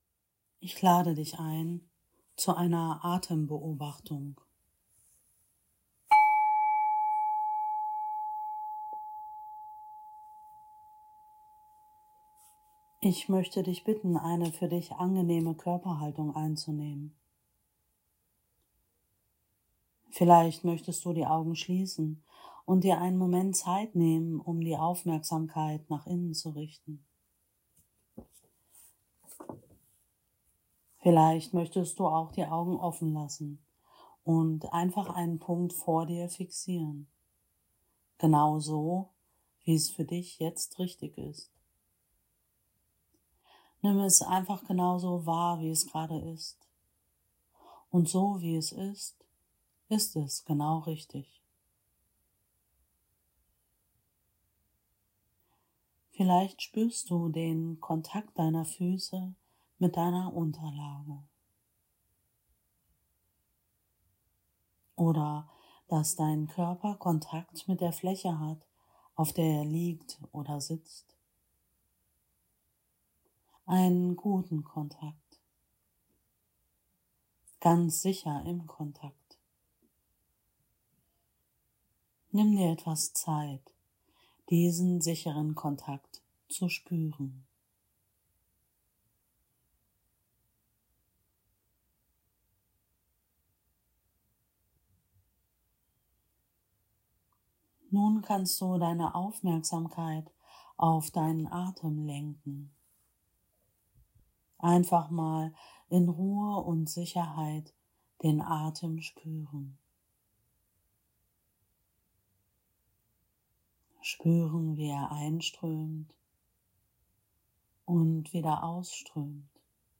Atembeobachtung
VALEARA_Atembeobachtung.mp3